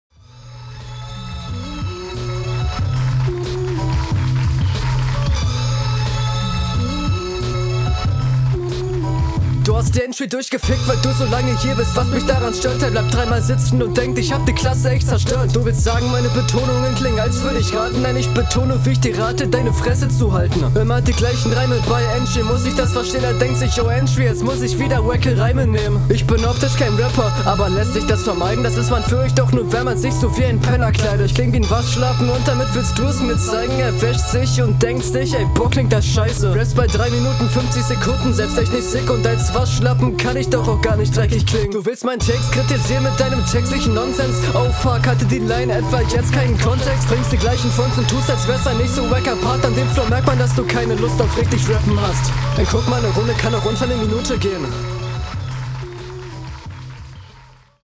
Flow: druckvoller als die hr was ich besser finde und mich mehr anspricht, im Grunde …